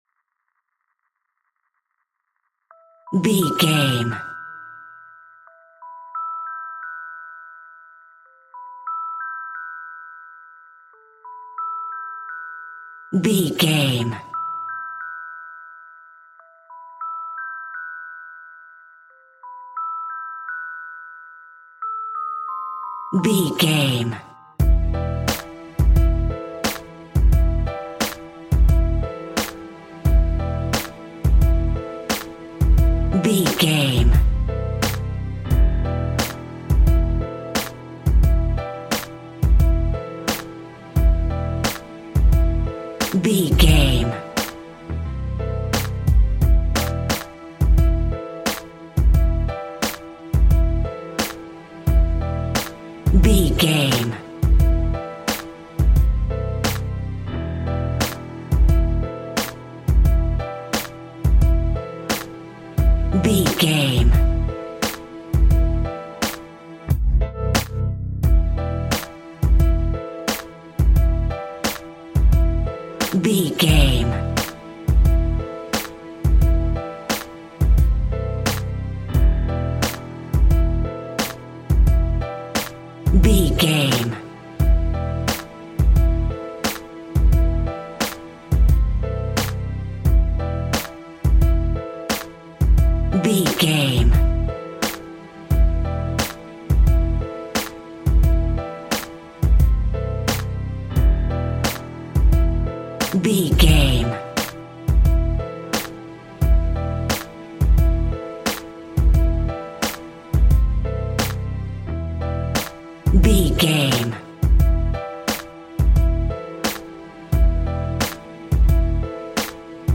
Thumping Hip Hop.
Aeolian/Minor
electronic
drum machine
synths